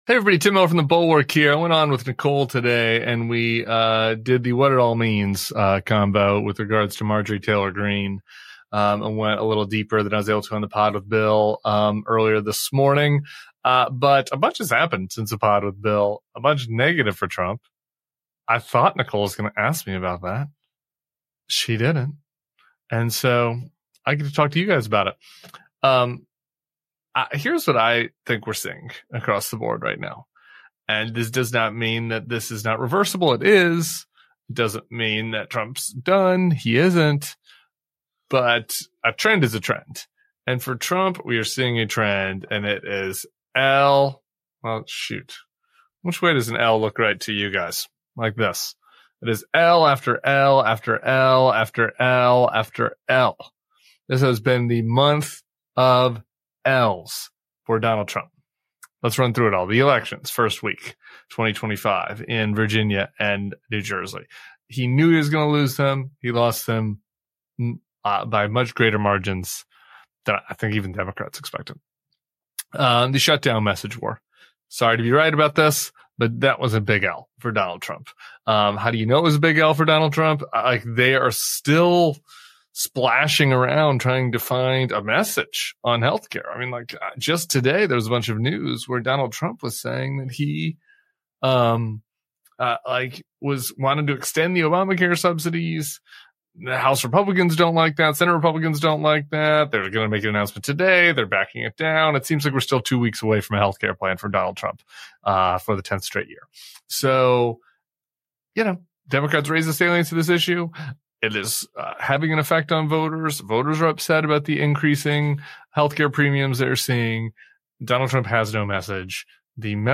Tim Miller joins MSNOW’s Deadline: White House to take on the growing alarm inside the GOP as Trump racks up losses and what MTG’s resignation means for the GOP and the 2026 elections.